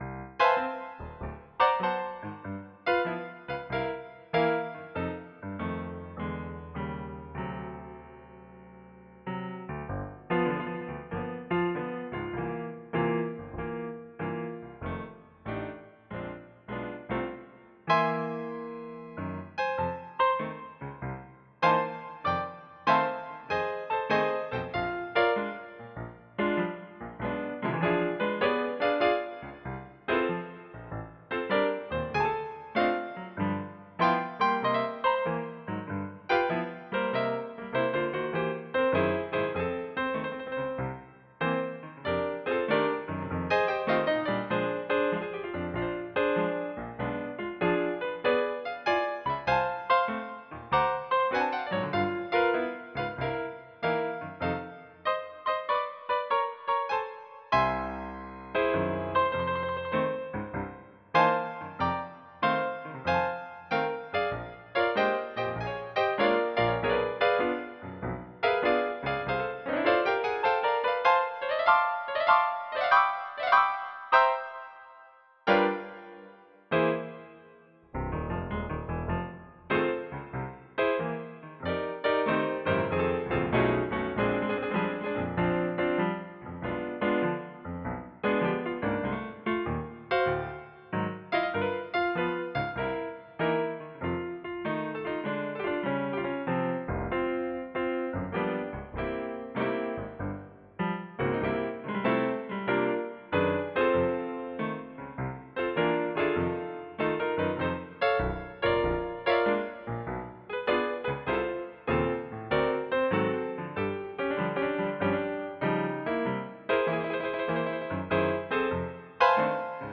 This page is about the most popular hymn at a Western-style wedding in Japan: What a Friend We Have In Jesus.